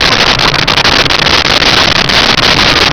Sfx Pod Chop A Loop
sfx_pod_chop_a_loop.wav